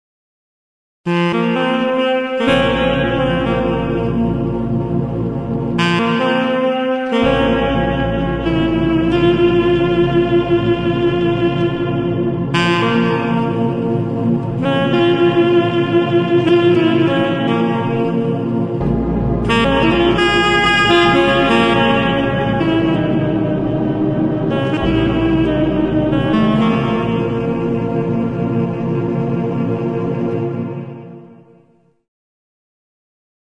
Josefův Důl, Tyl, Félicien Marceau: Vajíčko. Předehra k inscenaci.